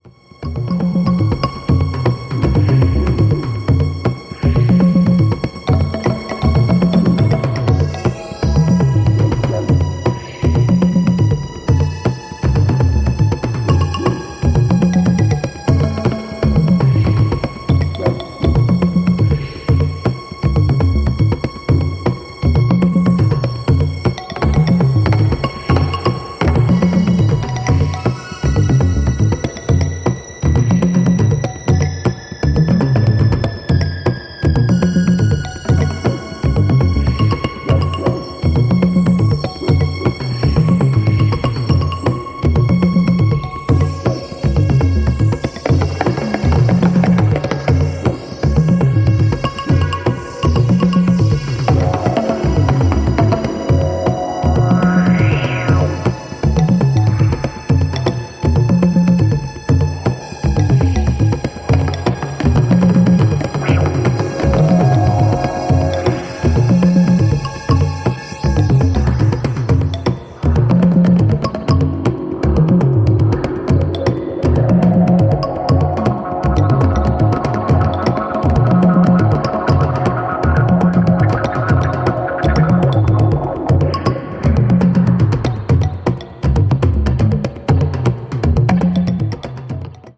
supplier of essential dance music
Dutch psych-rock artiste
the percussive culprit behind many a twisted tropical beat